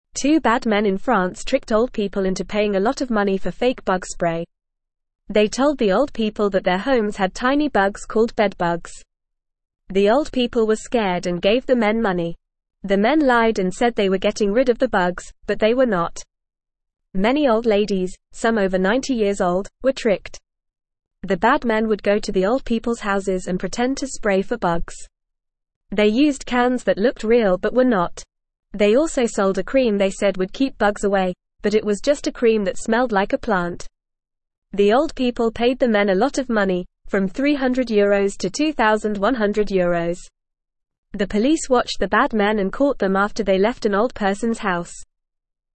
Fast
English-Newsroom-Beginner-FAST-Reading-Bad-men-trick-old-people-in-France-with-fake-bug-spray.mp3